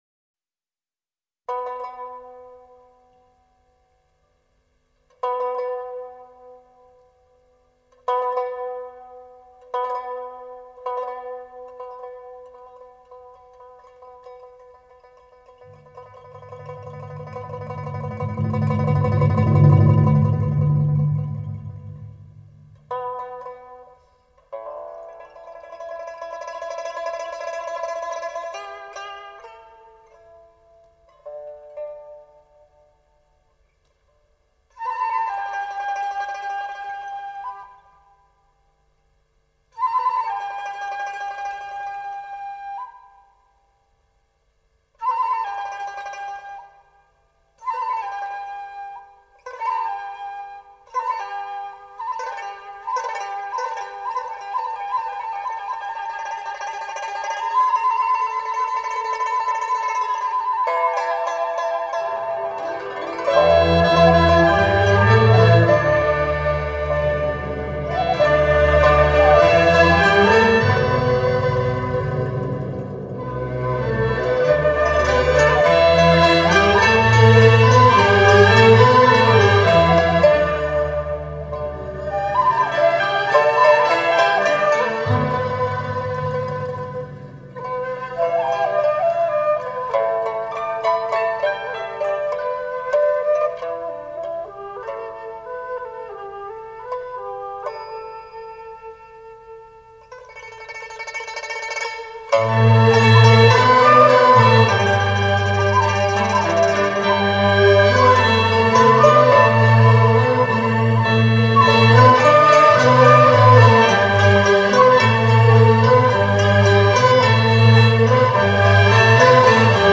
以巧妙细腻的配器与丝丝入扣的演奏，意境更为深远。
民乐合奏：通常指弹拨、吹管、拉弦、打击等诸种乐器分照不同声部的联合演奏方式。